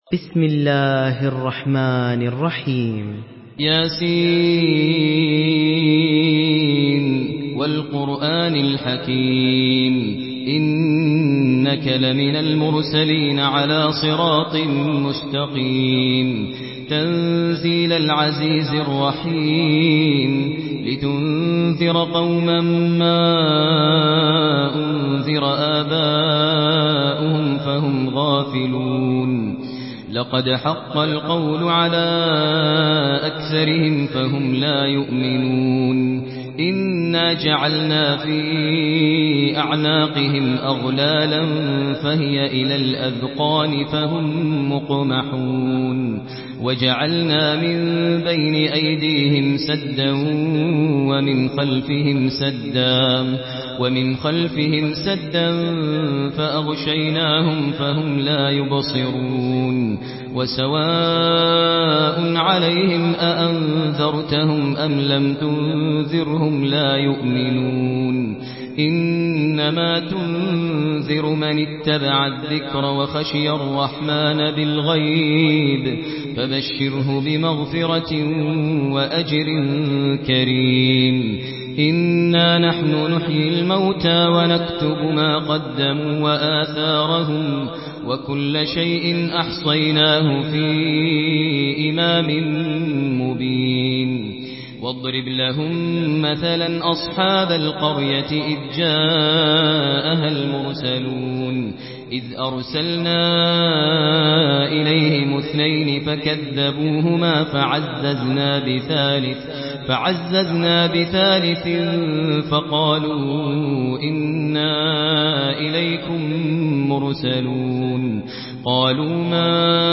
سورة يس MP3 بصوت ماهر المعيقلي برواية حفص
مرتل حفص عن عاصم